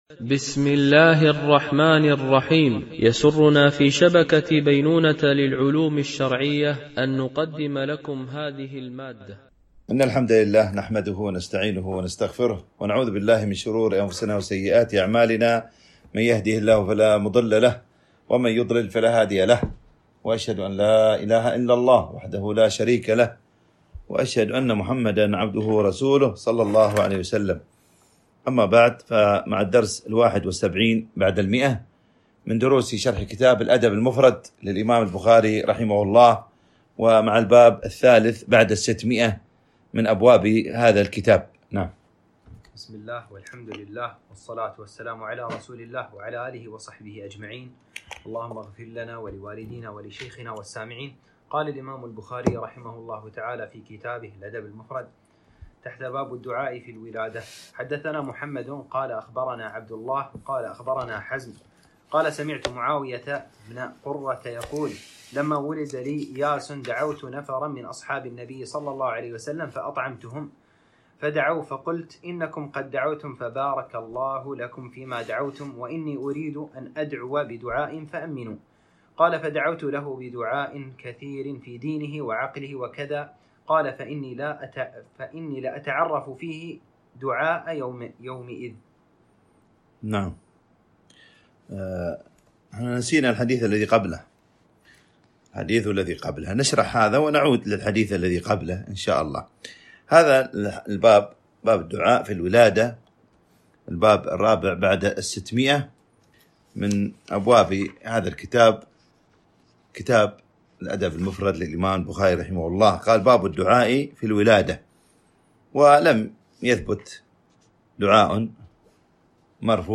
شرح الأدب المفرد للبخاري ـ الدرس 171 ( الحديث 1254-1258)